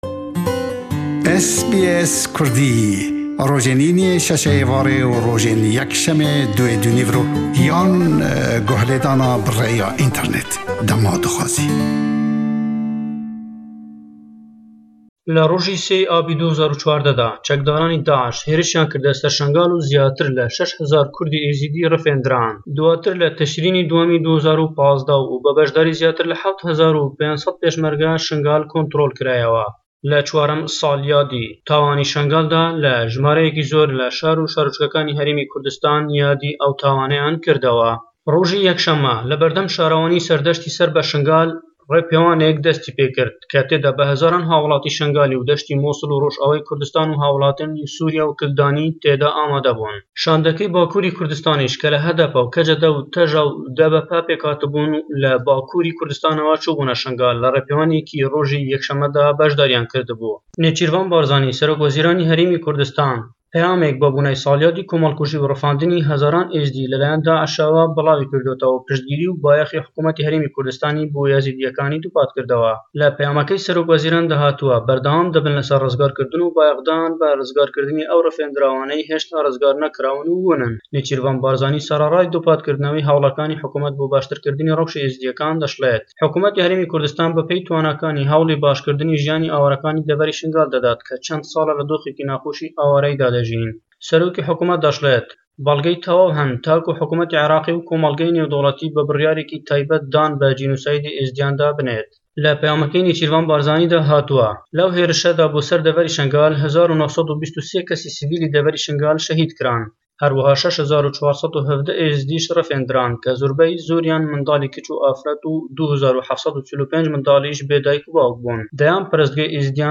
Raport ji Hewlêrê